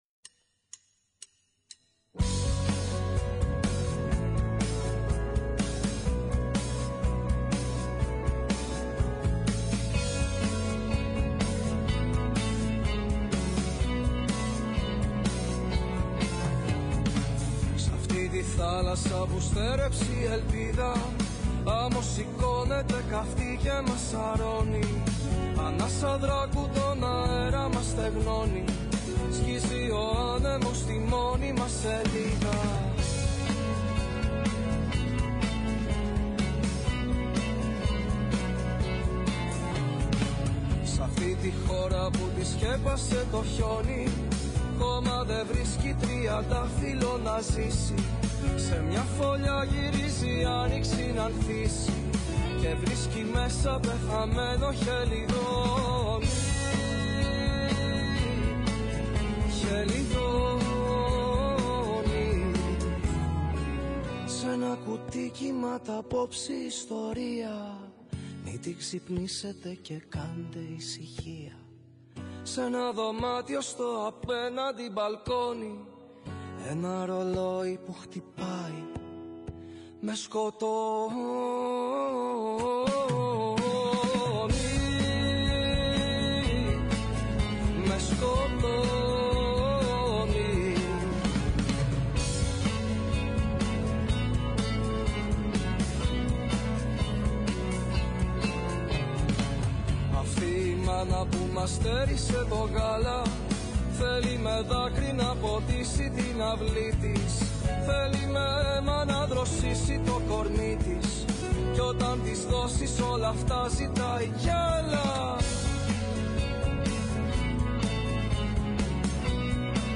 Στην εκπομπή ακούστηκαν τραγούδια από την προσωπική του δισκογραφία, αλλά και πρόσφατες διασκευές του.
ΔΕΥΤΕΡΟ ΠΡΟΓΡΑΜΜΑ Το Κλειδι του Sol Μουσική Συνεντεύξεις